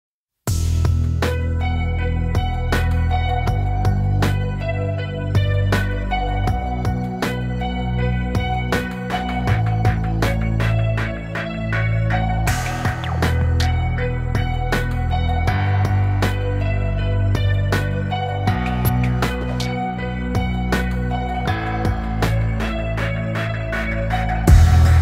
рок-группы